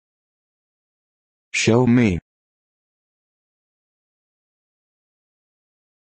Xiaomi pronunciation
Xiaomi pronunciation – Shi-yaw-mee – Shaomi
xiaomi-pronunciation.mp3